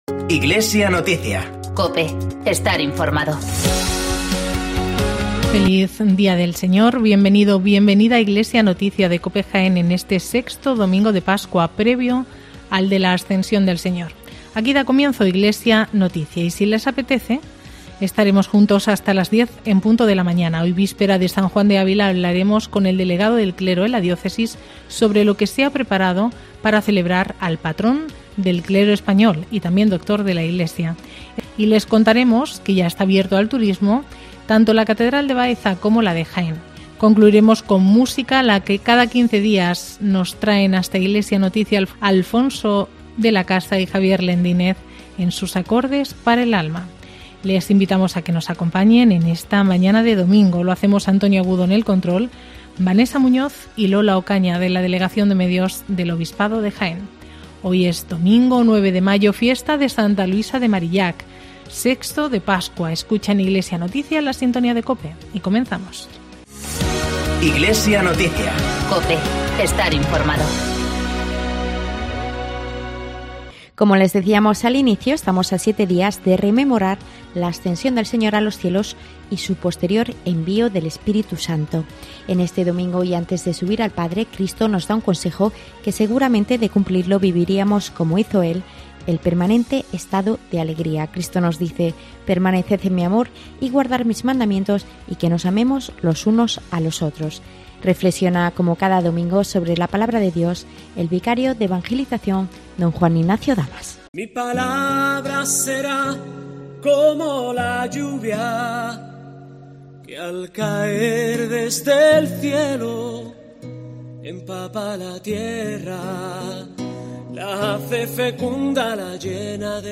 Informativo Iglesia Noticia